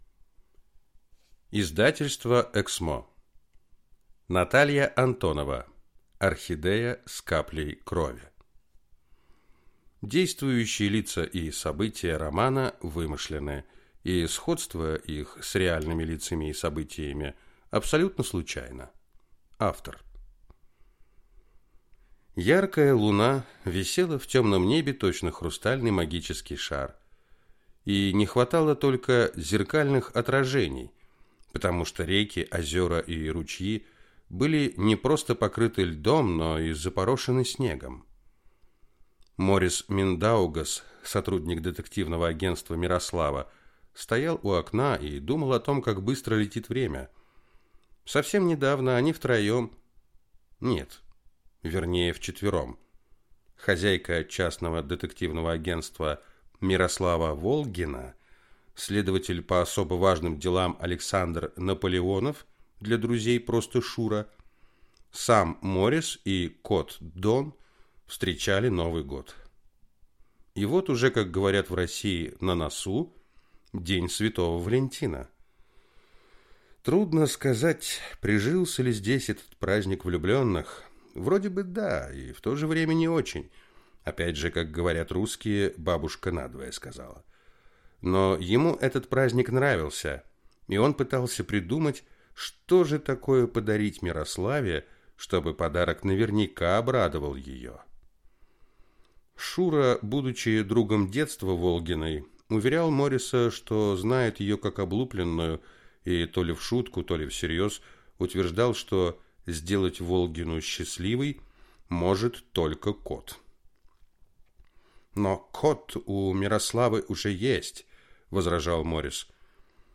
Аудиокнига Орхидея с каплей крови | Библиотека аудиокниг